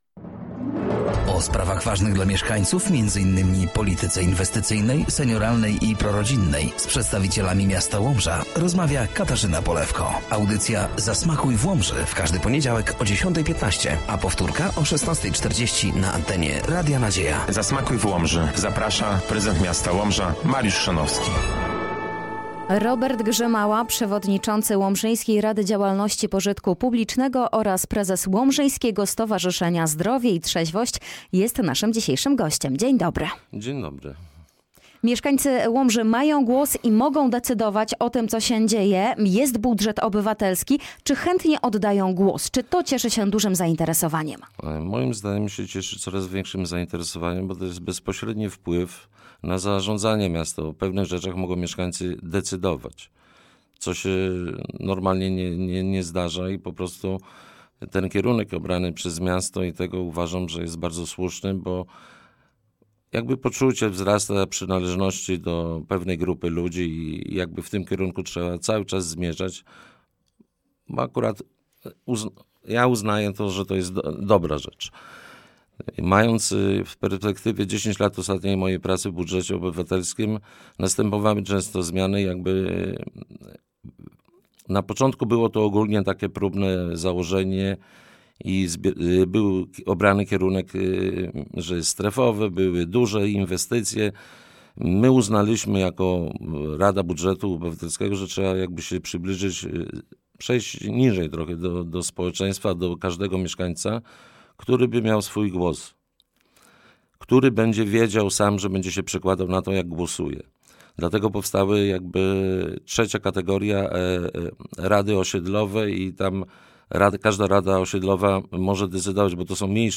Audycja „Zasmakuj w Łomży”, w każdy poniedziałek o 10.15 na antenie Radia Nadzieja.